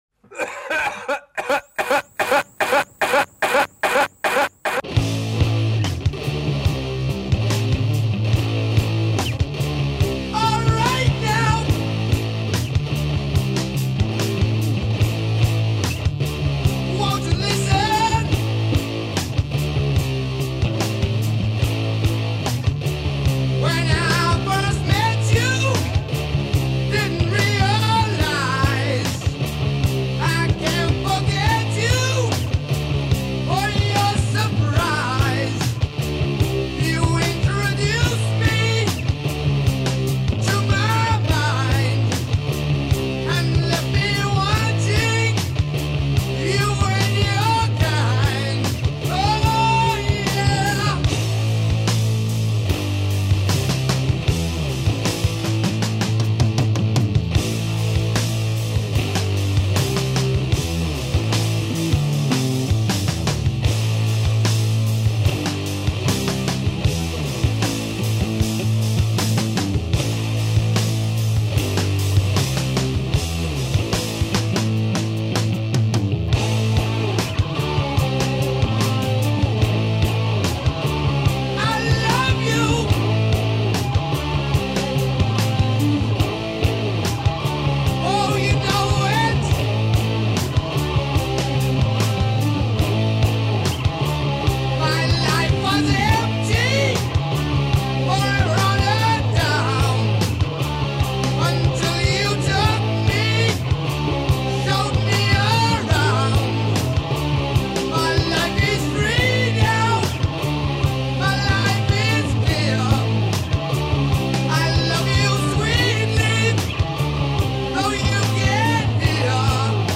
Heavy Metal, Doom Metal, Stoner Rock